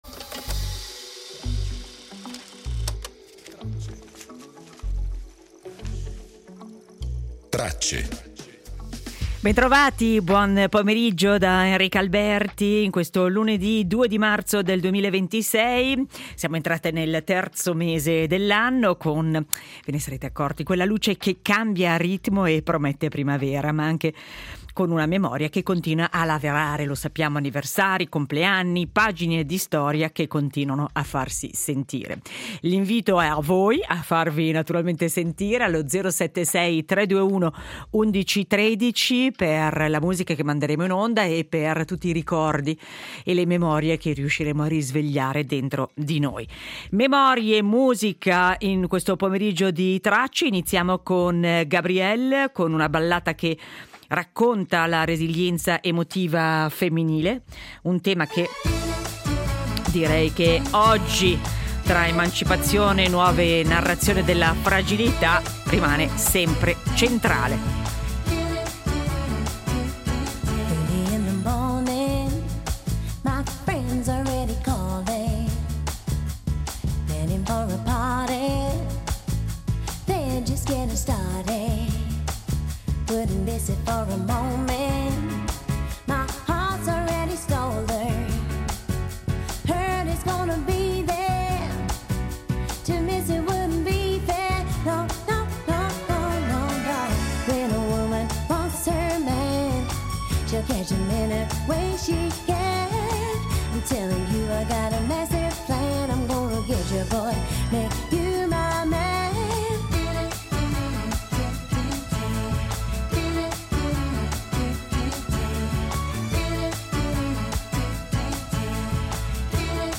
Riascoltiamo una cronaca a caldo del 2003: il momento in cui la Coppa America, per la prima volta, parla anche svizzero con il team Alinghi guidato da Ernesto Bertarelli, il momento in cui un paese senza mare ha dimostrato di poter dominare l’oceano. In previsione del primo Gran Premio di Formula 1 del 2026 (si terrà in Australia l’8 marzo) ci rituffiamo nella vettura da Formula 1 del 1975 raccontata dal suo pilota: Clay Regazzoni al microfono di Mike Bongiorno.